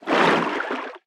Sfx_creature_snowstalker_swim_03.ogg